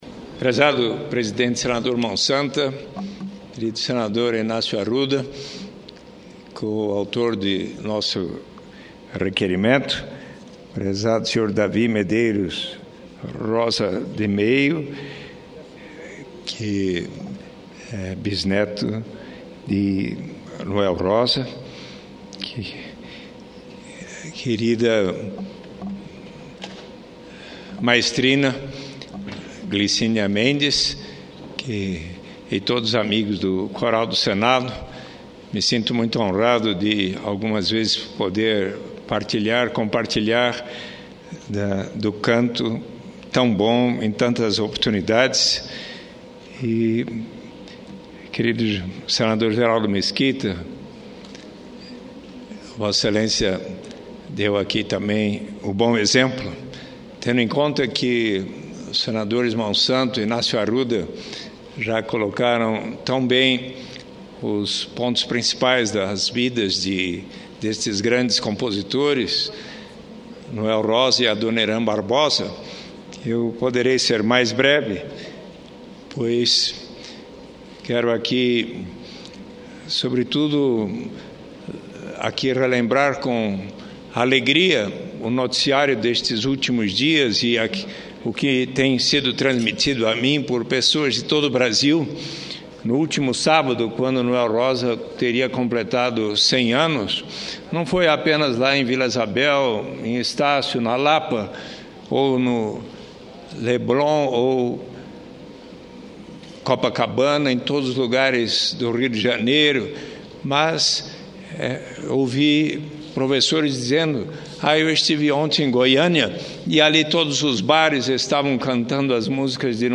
Discurso do senador Eduardo Suplicy